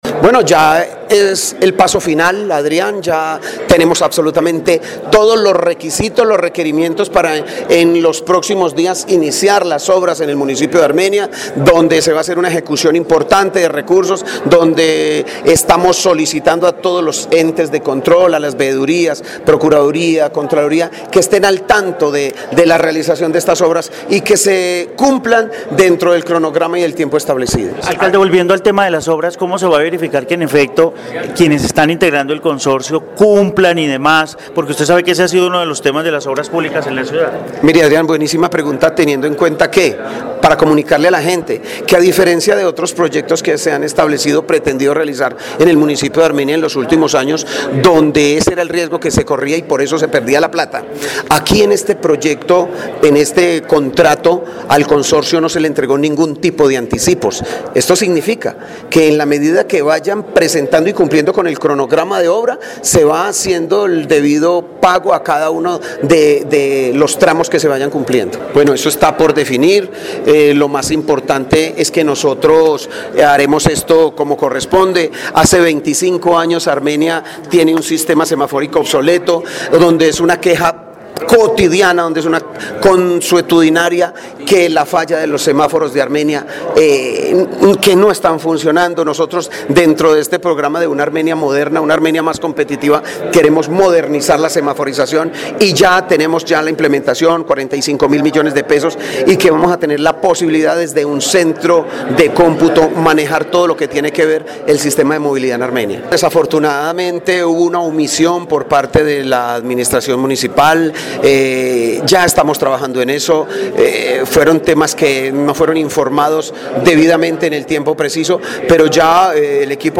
James Padilla García, alcalde de Armenia